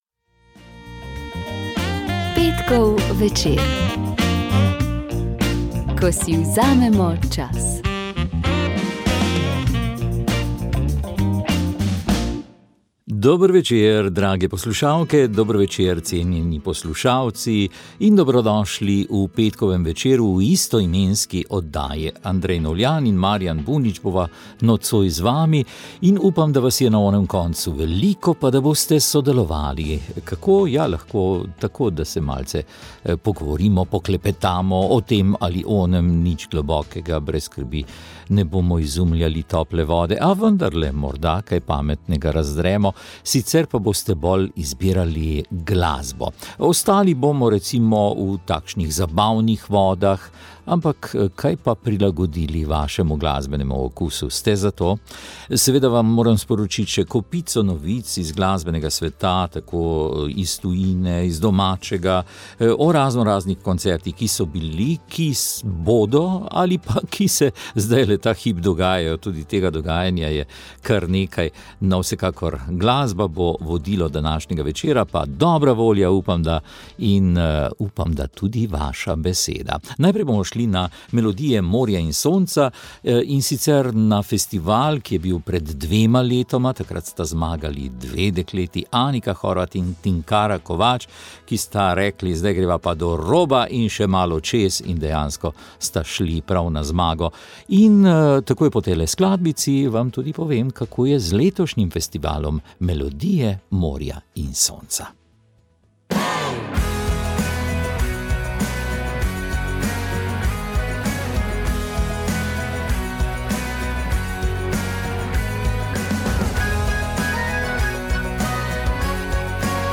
pogovor
glasba pop